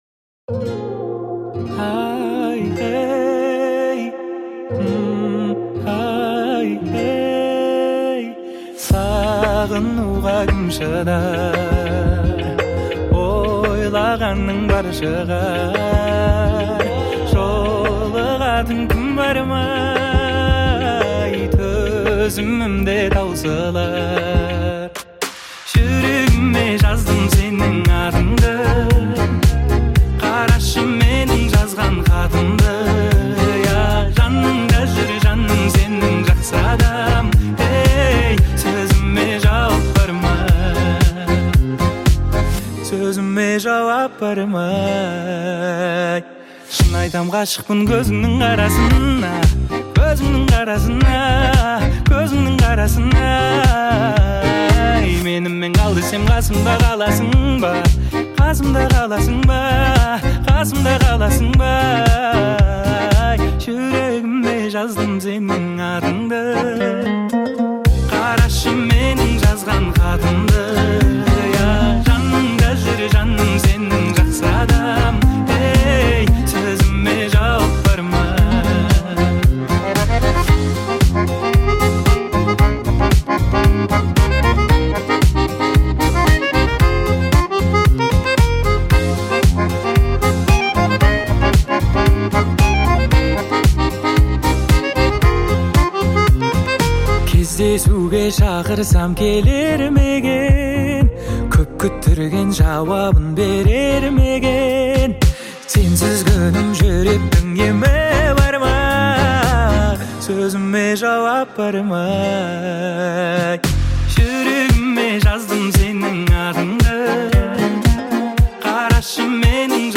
• Категория: Казахская музыка